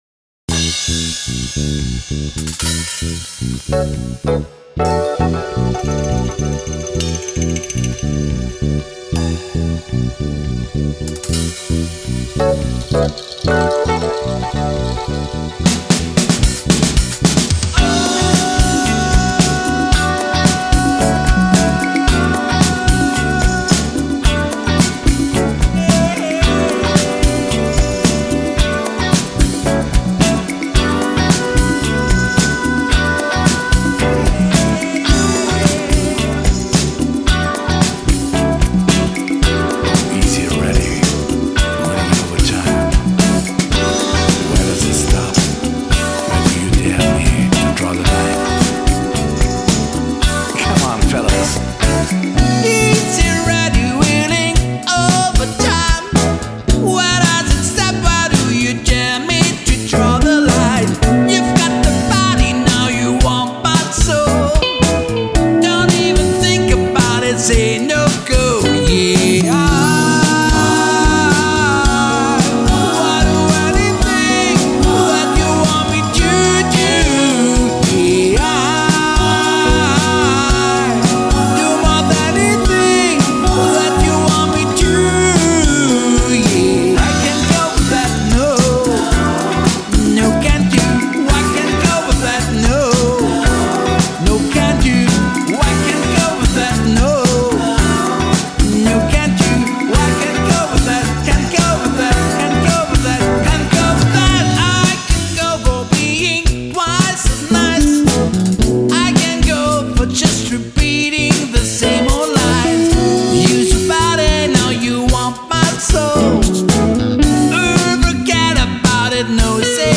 Nicht alles was leicht klingt ist auch leicht gespielt…